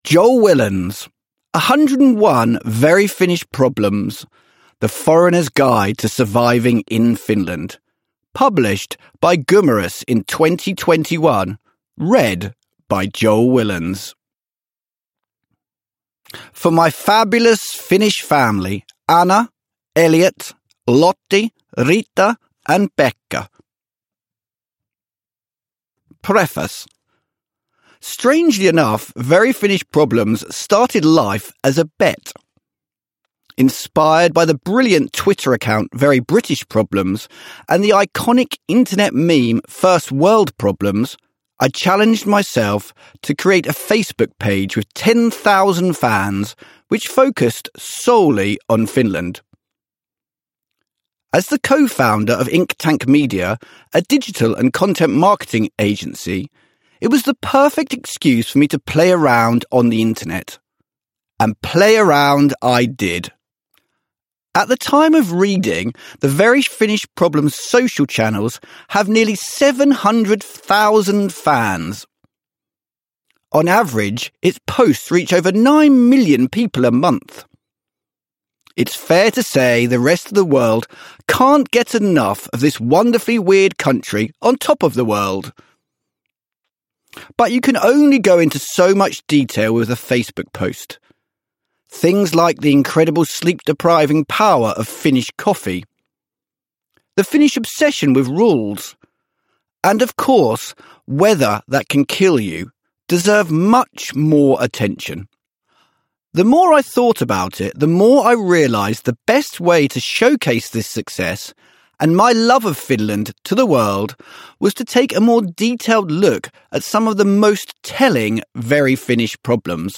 101 Very Finnish Problems – Ljudbok – Laddas ner